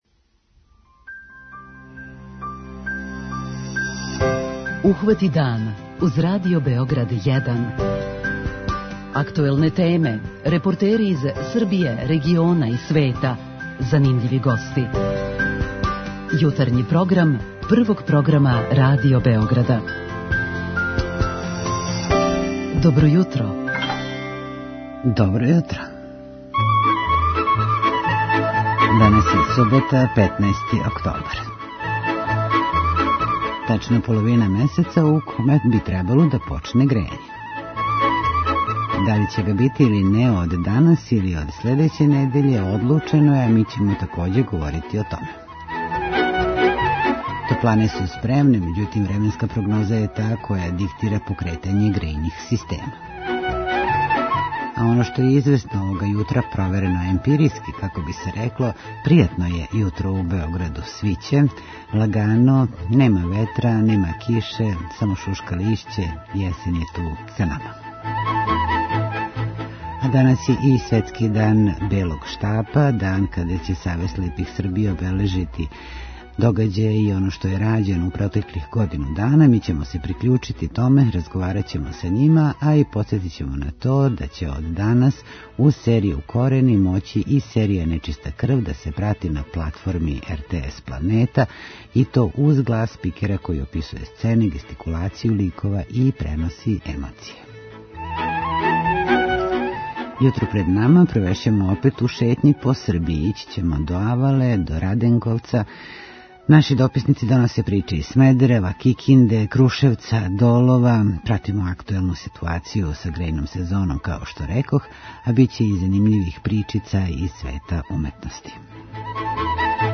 Говорићемо у јутарњем програму - о ауто трци на Авали и Раденковању - које ове године слави 10-ти рођендан. Наши дописници доносе приче из Смедерева, Кикинде и Крушевца. Пратимо актуелну ситуацију са почетком грејне сезоне, а доносимо и занимљивости из света ликовне уметности.
преузми : 26.96 MB Ухвати дан Autor: Група аутора Јутарњи програм Радио Београда 1!